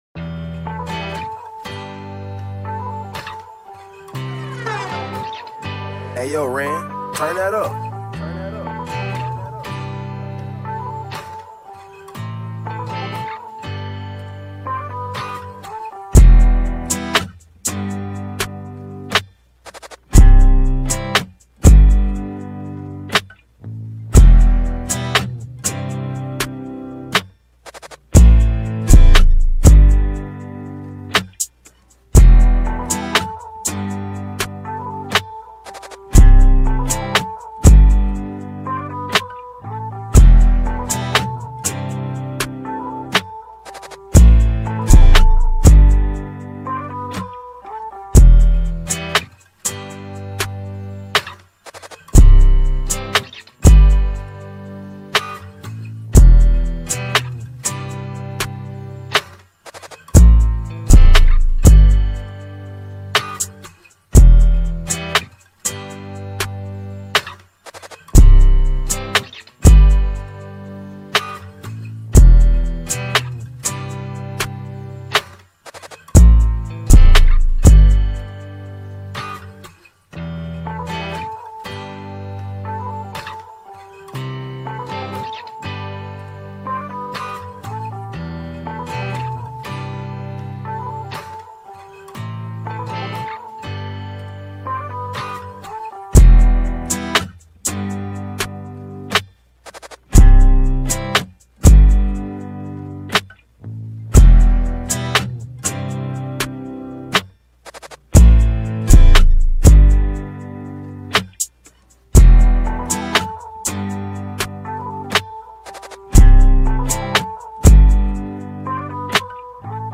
2020 in Hip-Hop Instrumentals